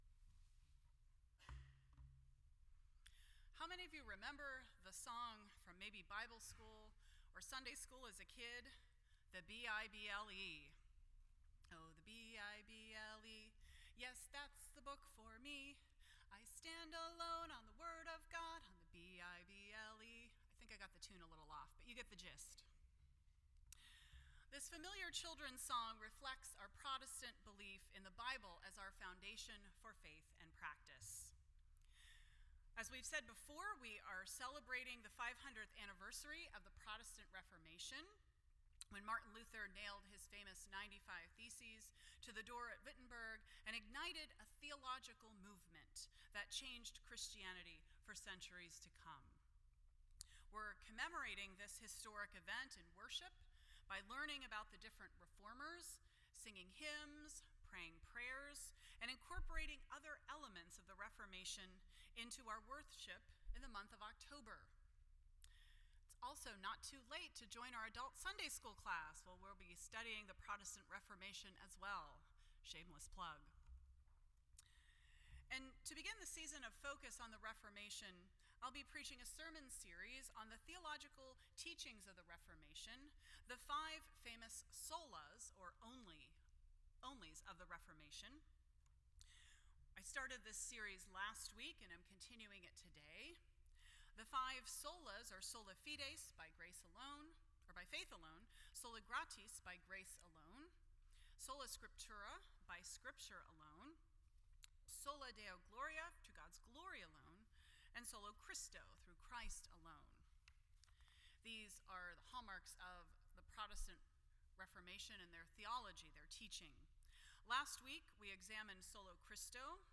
The Five Solas (Onlys) of the Protestant Reformation Service Type: Sunday Morning %todo_render% Share This Story